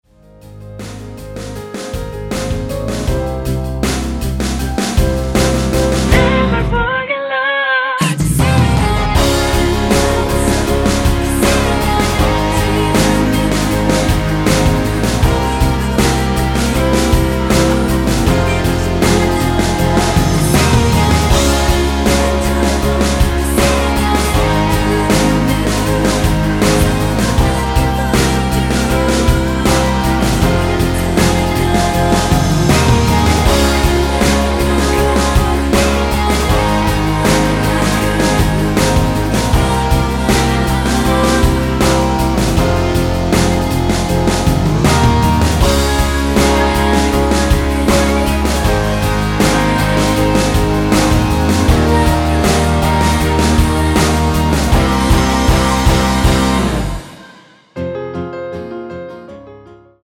원키에서(-1)내린 코러스 포함된 MR입니다.
Fm
앞부분30초, 뒷부분30초씩 편집해서 올려 드리고 있습니다.
중간에 음이 끈어지고 다시 나오는 이유는